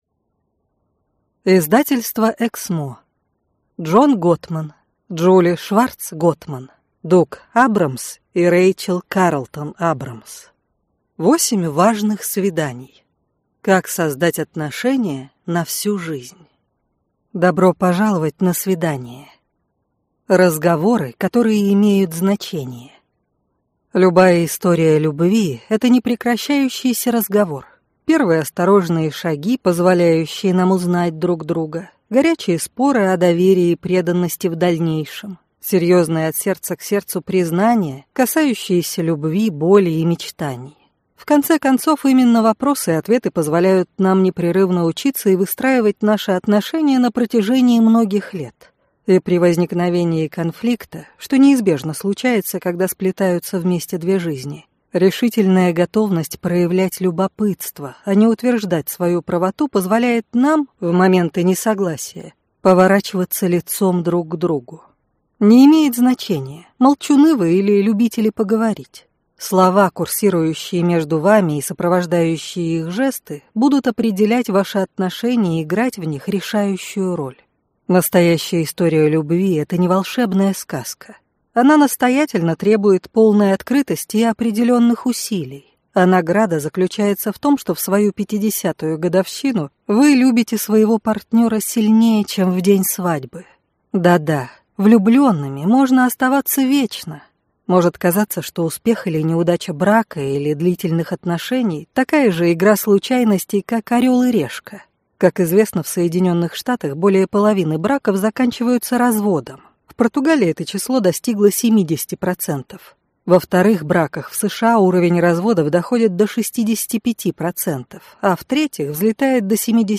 Аудиокнига 8 важных свиданий. Как создать отношения на всю жизнь | Библиотека аудиокниг
Прослушать и бесплатно скачать фрагмент аудиокниги